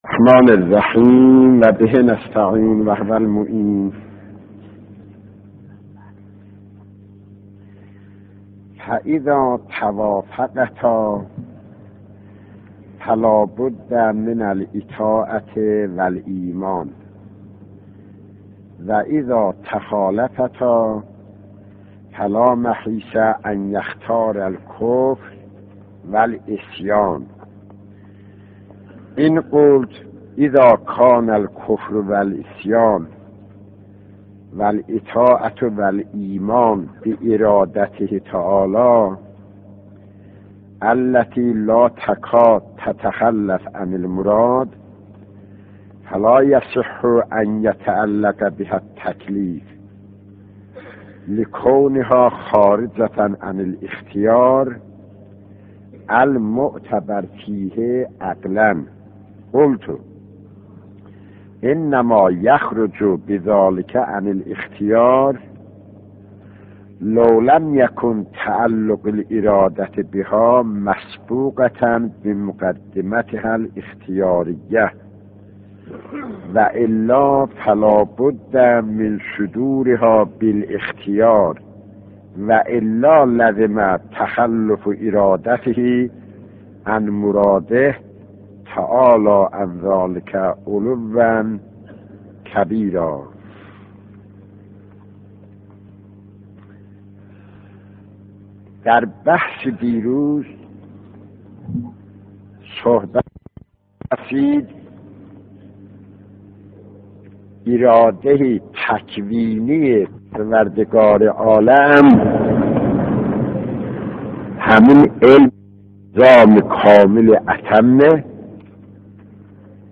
دانلود دروس حوزه پایه 9 = صوتی